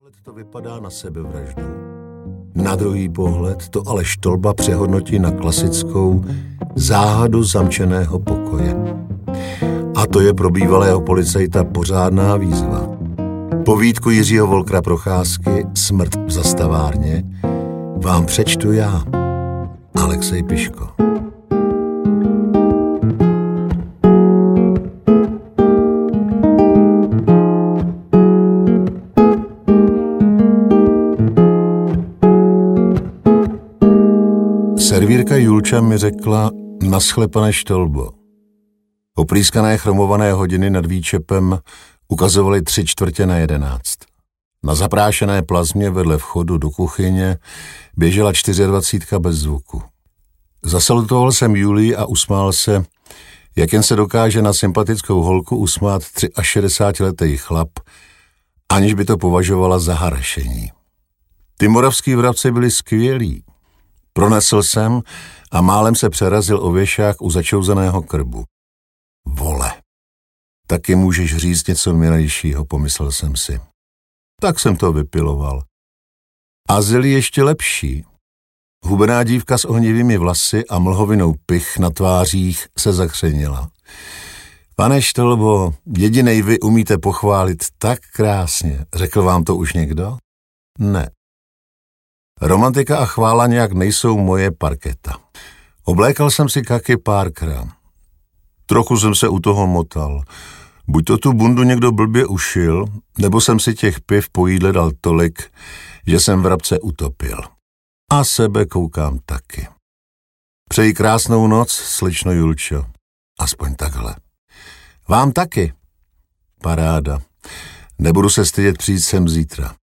Česko Rozmarné audiokniha
Ukázka z knihy